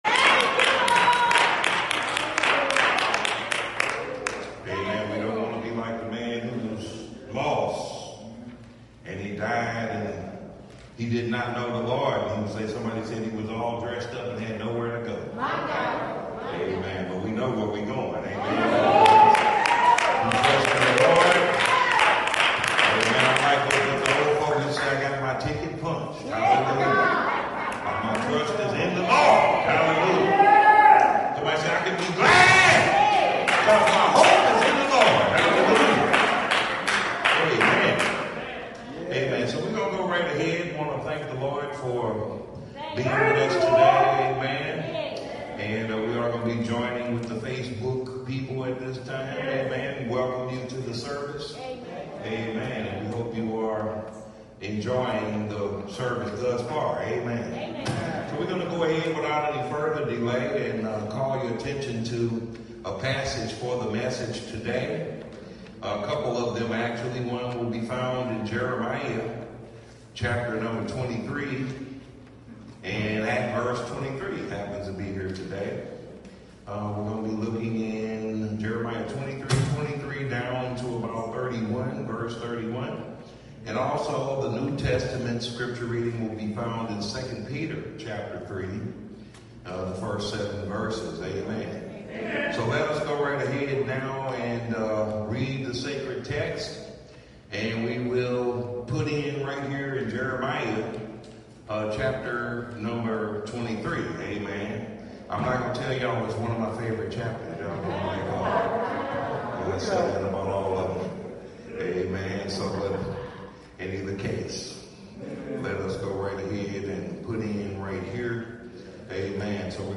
The Eternal Objective Voice Of The Bible Wednesday Live-Stream